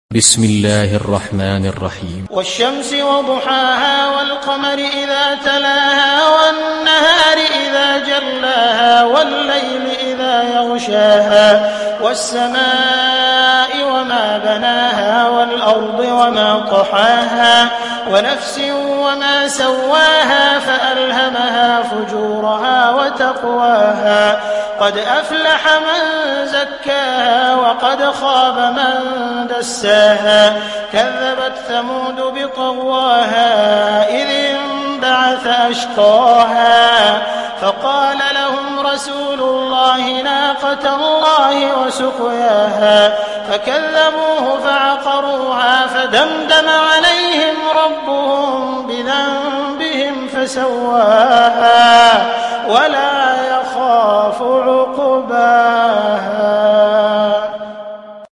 Sourate Ash Shams Télécharger mp3 Abdul Rahman Al Sudais Riwayat Hafs an Assim, Téléchargez le Coran et écoutez les liens directs complets mp3